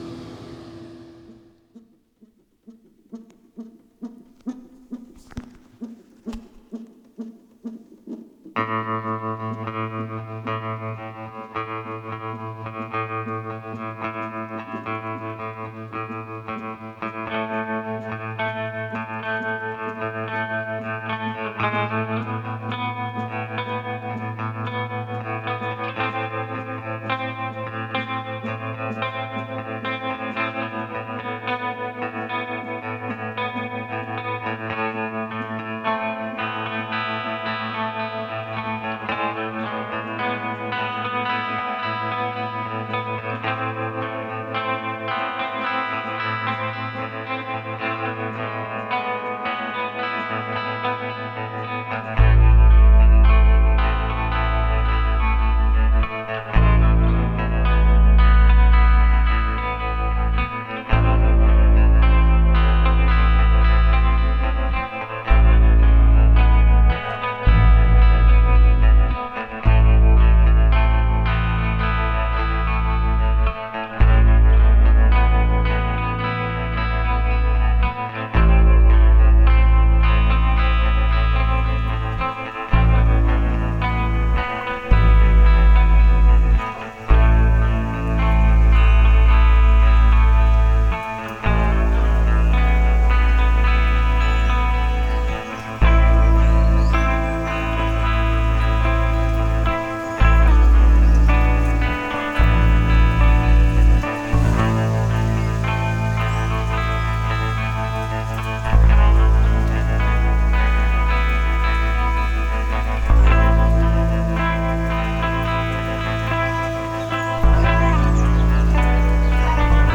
bass, keyboards, kinderklavier
keyboards, schlagzeug, bass
schlagzeug, percussion
gitarren, gesang, bass, keyboards